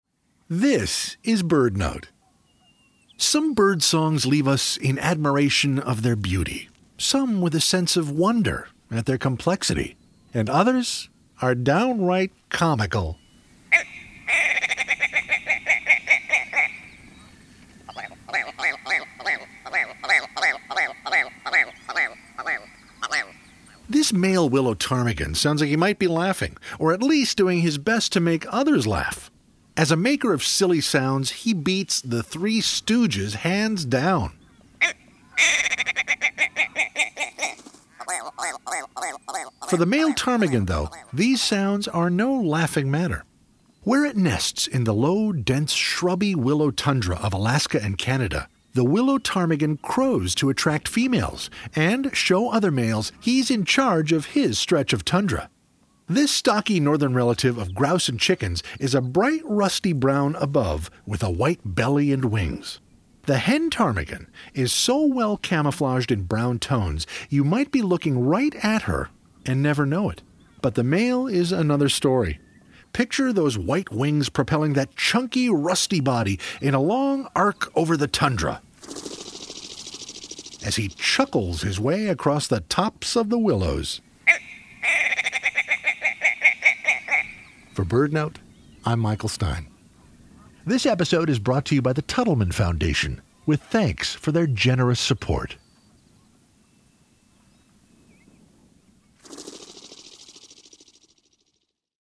Some bird songs leave us in admiration of their beauty, some with a sense of wonder at their complexity—and others are downright comical. As a maker of silly sounds, the male Willow Ptarmigan beats the Three Stooges hands down.